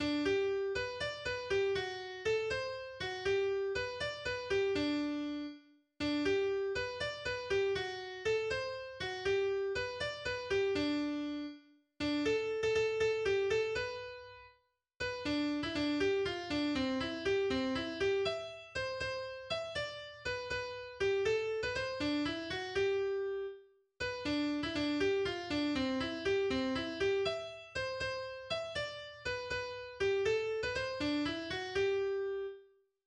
Das Trinklied
Gesungen wird das Lied auf eine alte Volksweise